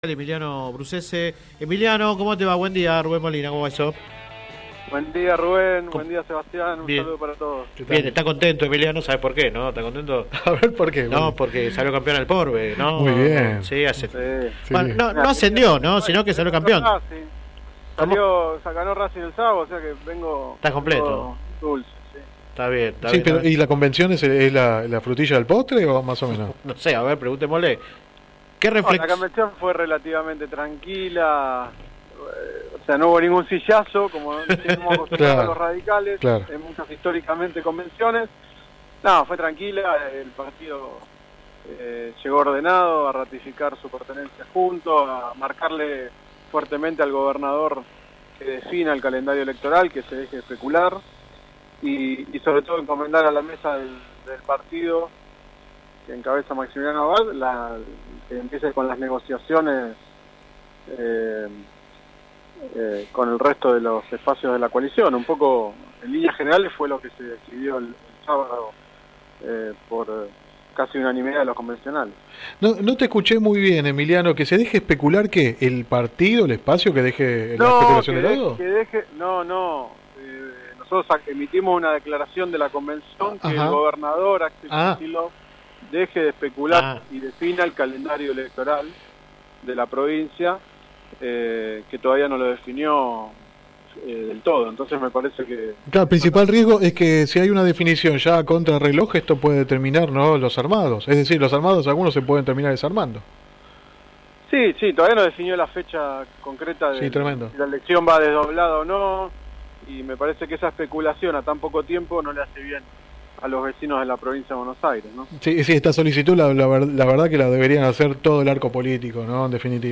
El dirigente radical, que habló en el programa radial Sin Retorno (lunes a viernes de 10 a 13 por GPS El Camino FM 90 .7 y AM 1260), también se refirió a la reciente convención provincial de su partido.
Click acá entrevista radial